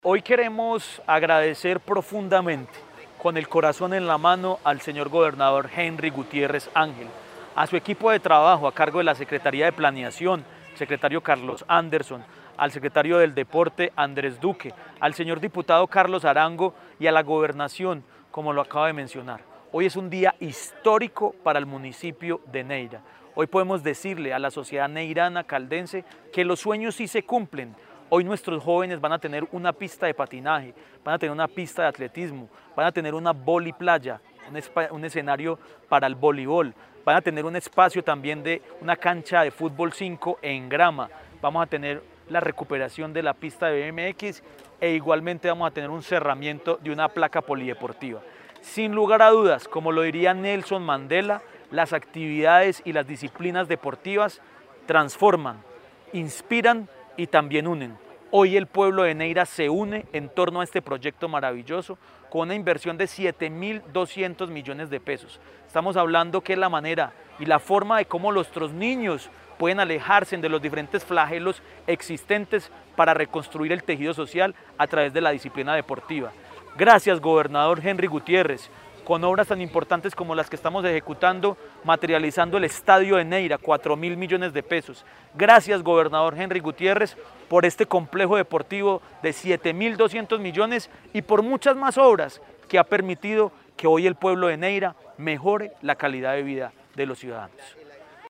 Jhon Jairo Flores, alcalde municipal de Neira.
Jhon-Jairo-Flores-alcalde-municipal-de-Neira.mp3